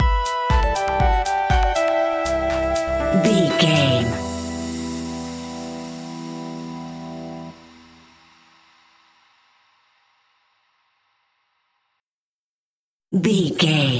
Aeolian/Minor
uplifting
driving
energetic
happy
bouncy
synthesiser
drum machine
electro house
progressive house
synth leads
synth bass